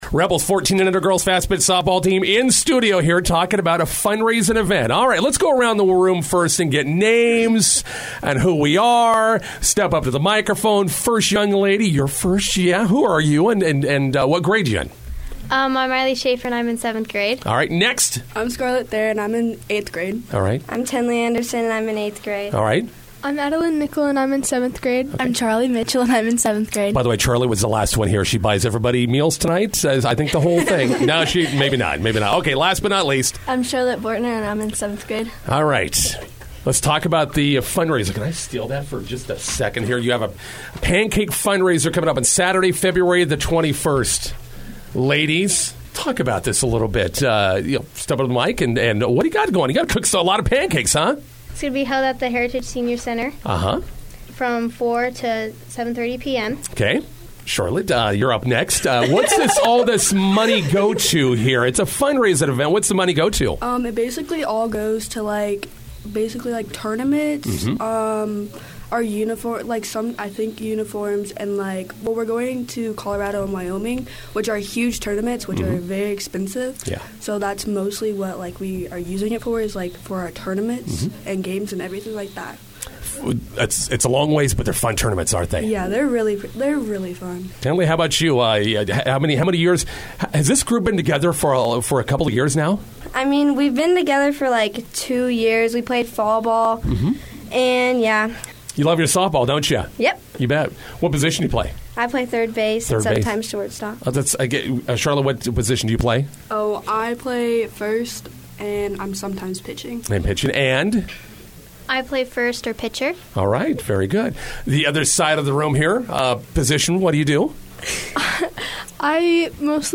INTERVIEW: McCook Rebels 14s hosting a pancake fundraiser this weekend at the McCook Senior Center.